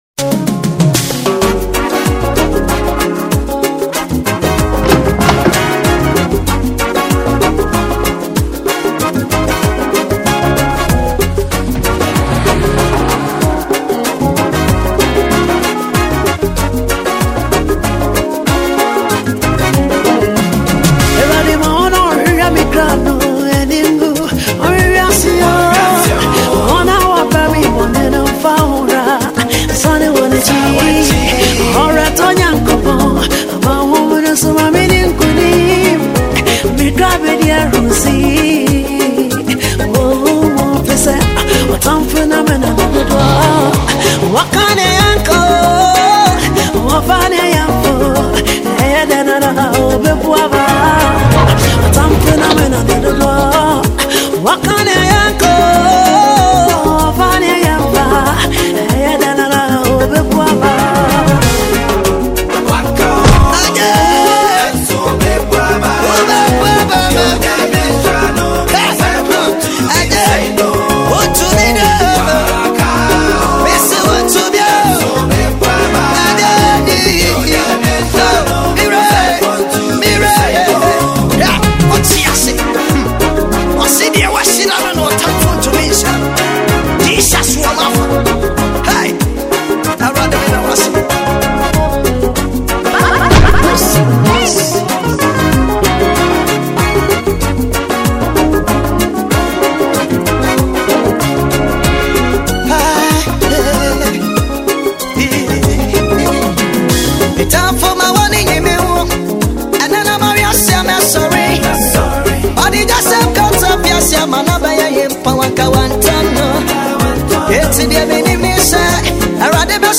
Ghana gospel music